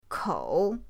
kou3.mp3